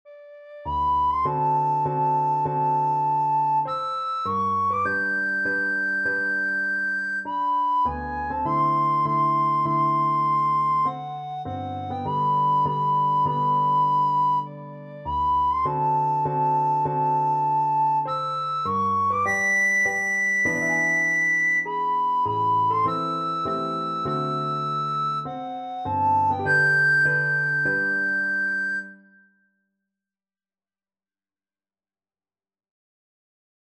Free Sheet music for Soprano (Descant) Recorder
3/4 (View more 3/4 Music)
Classical (View more Classical Recorder Music)